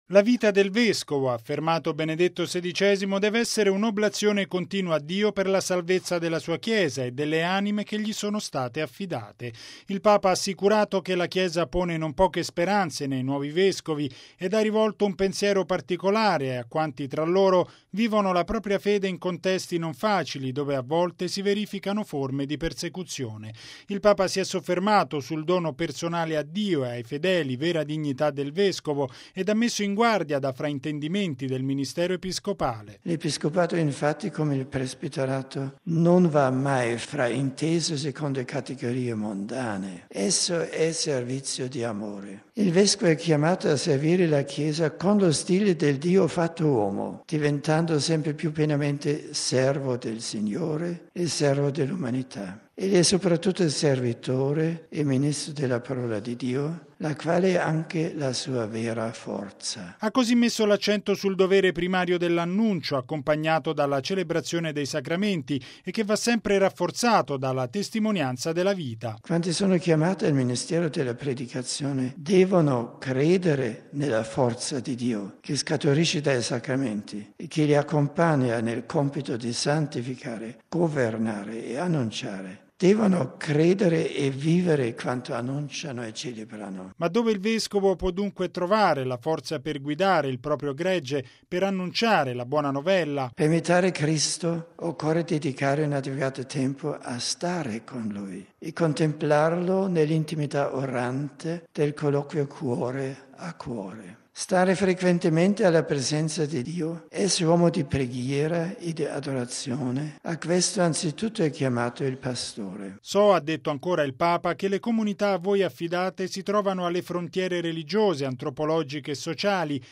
◊   L’episcopato è un servizio d’amore: è quanto sottolineato da Benedetto XVI nel discorso di stamani, a Castel Gandolfo, ai vescovi di recente nomina, che hanno partecipato al Corso promosso dalla Congregazione per l’Evangelizzazione dei Popoli. Il Papa ha quindi esortato i presuli a non considerare il proprio ministero secondo categorie mondane.